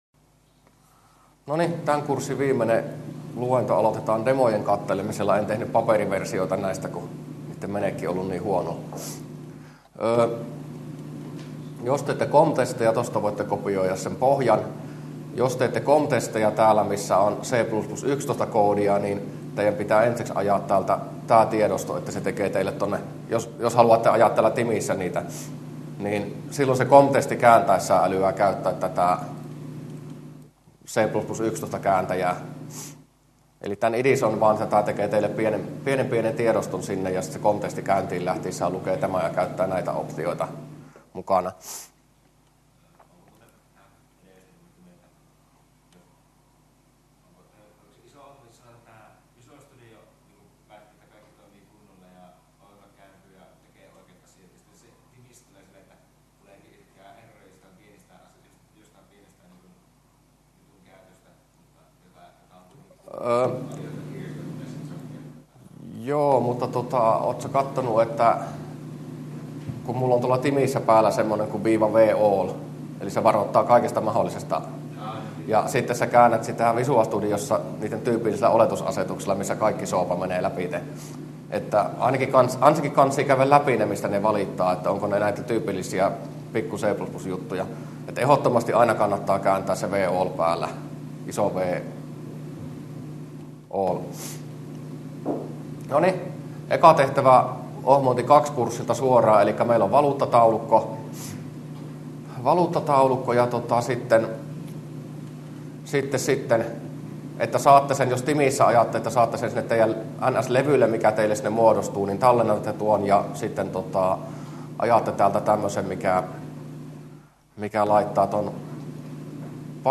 luentoC4a